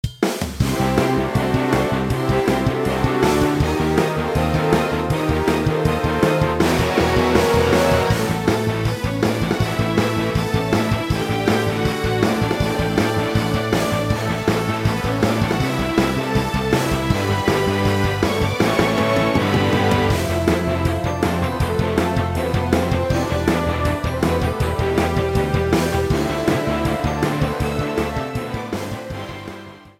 Trimmed and fade out